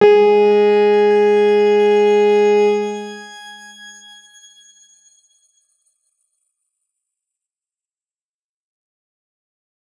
X_Grain-G#3-mf.wav